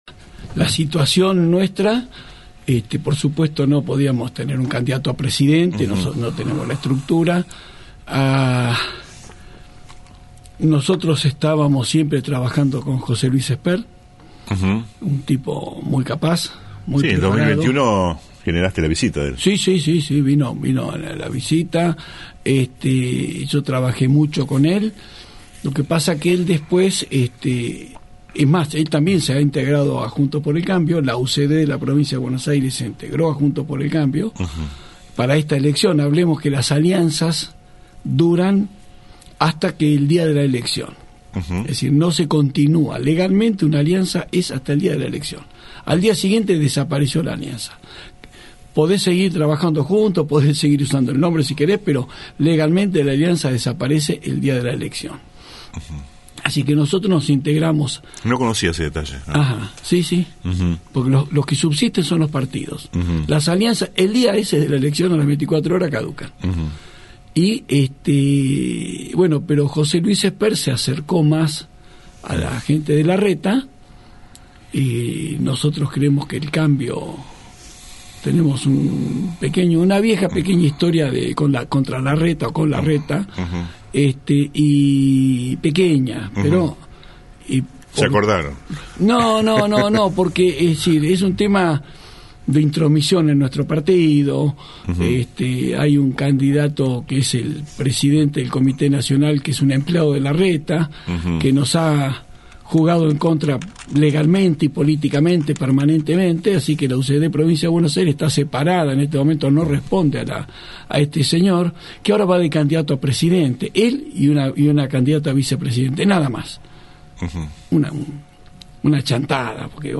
En la mañana de “El Periodístico” y ante la recepción de una nota sobre el apoyo político a la dirigente nacional Patricia Bullrich, hablamos con el ex legislador provincial por los liberales, el Arq. Fernando Camara.
ENTREVISTA COMPLETA A FERNANDO CAMARA